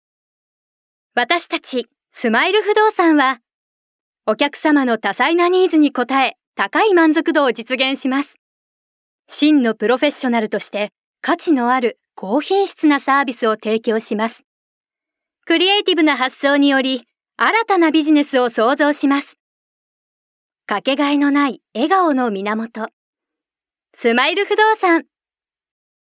◆企業ナレーション１◆
◆企業ナレーション２◆
◆自然番組ナレーション◆